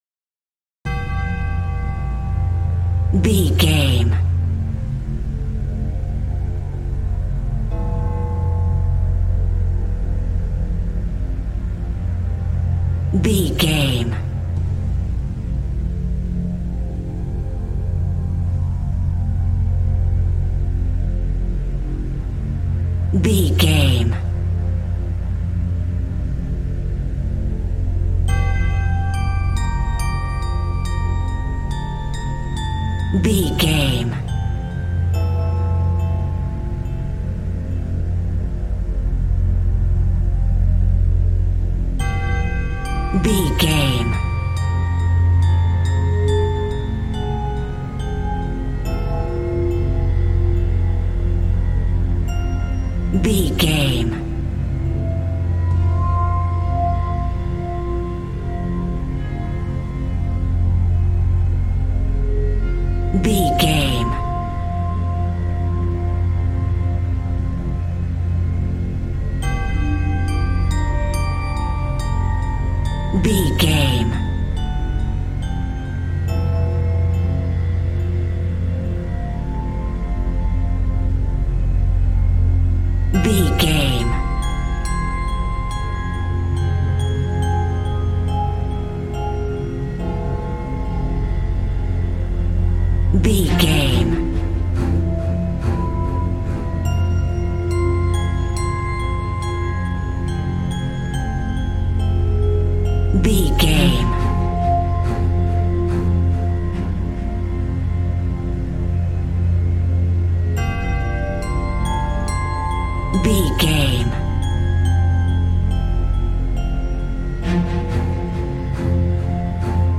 Ionian/Major
tension
suspense
dark
piano
synthesiser